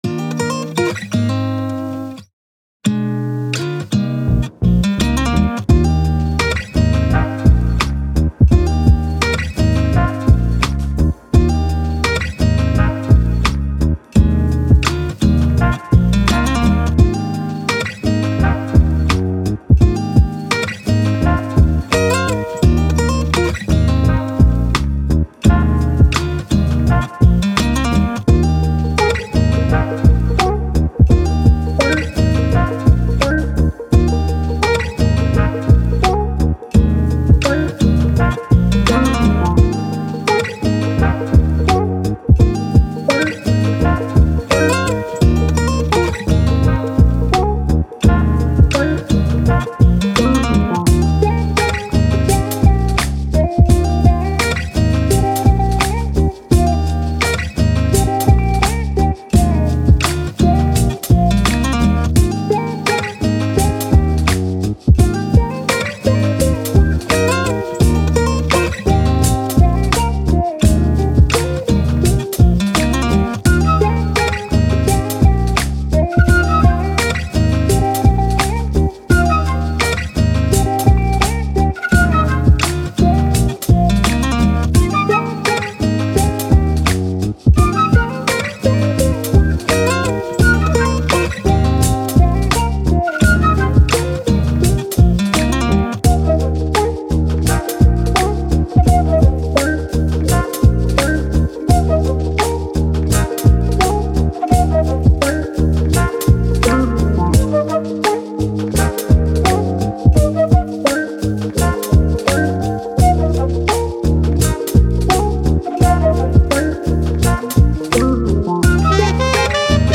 Soul, Pop, Happy, Joy, Upbeat